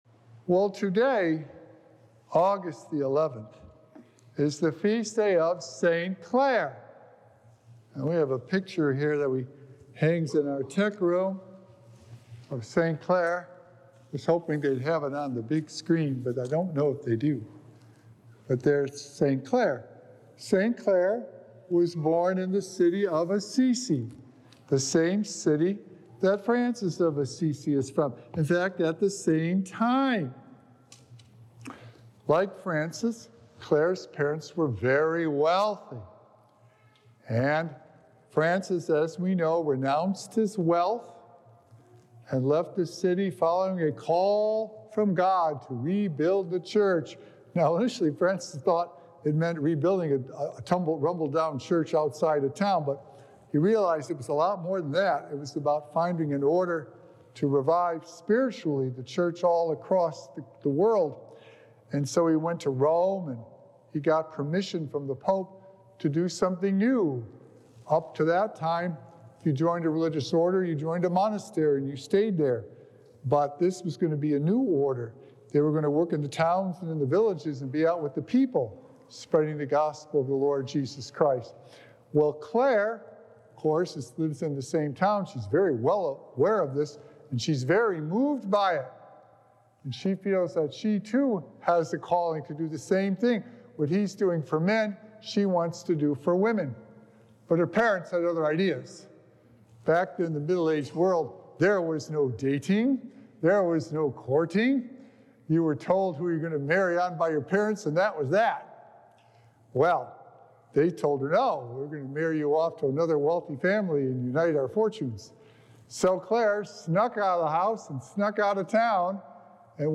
Sacred Echoes - Weekly Homilies Revealed